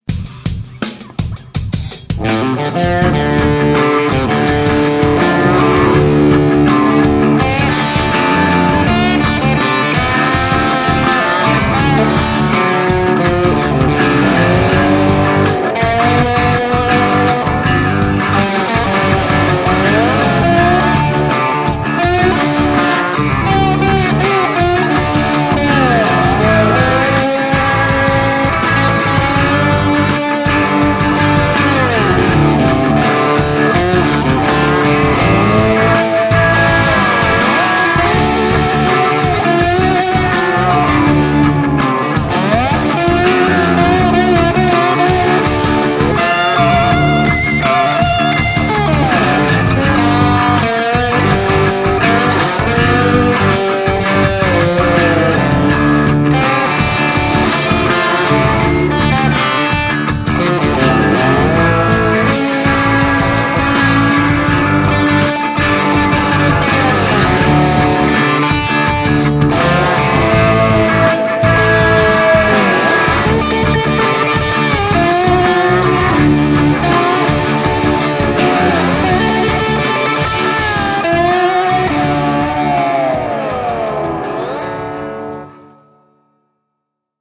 Jatketaan vähän rouheammalla meiningillä:
- kun osallistut, soita roots-soolo annetun taustan päälle ja pistä linkki tähän threadiin